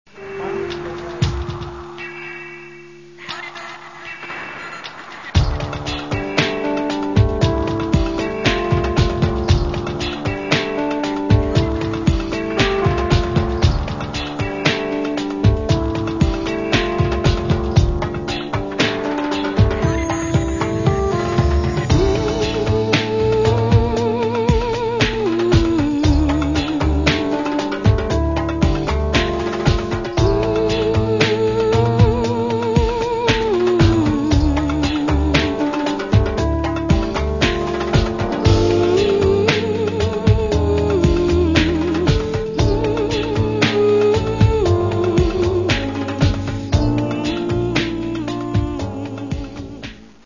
Каталог -> Поп (Легка) -> Fashion